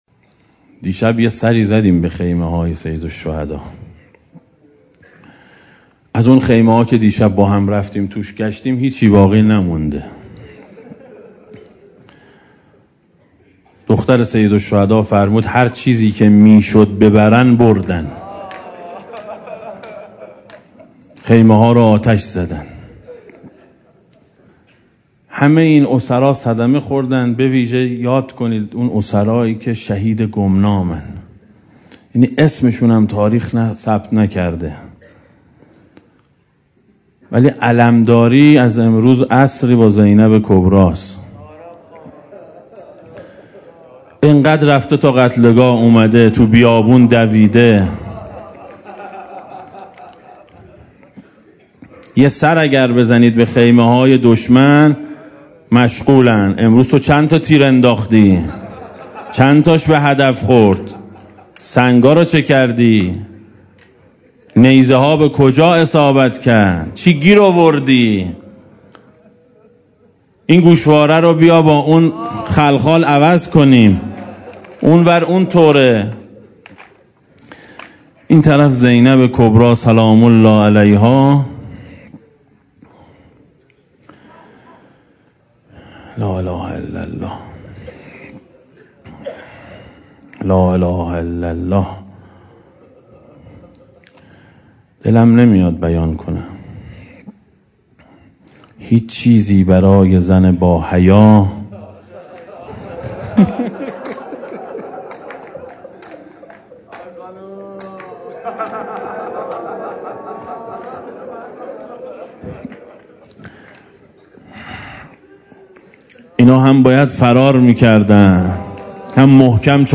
روضه شب یازدهم محرم سال 1395 ـ مجلس دوم